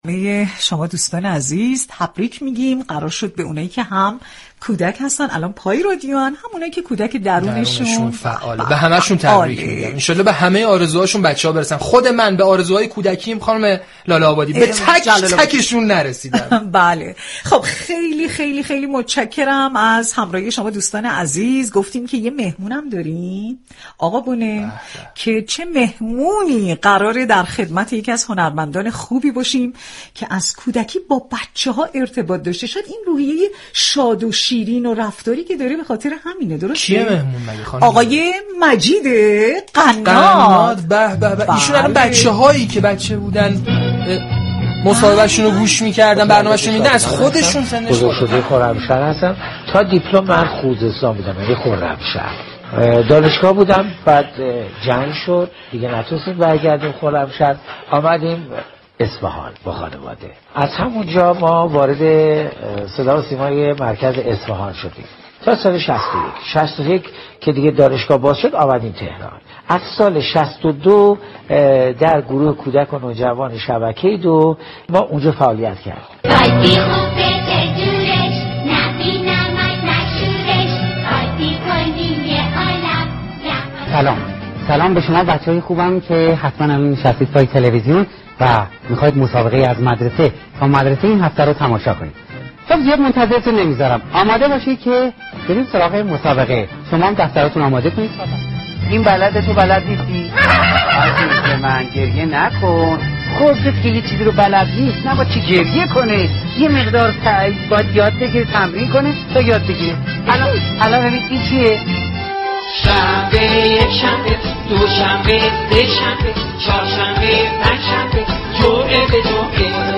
برنامه عصر صبا در روز جهانی كودك میزبان مجید قناد تهیه‌كننده و كارگردان و مجری برنامه‌های كودك و نوجوان شد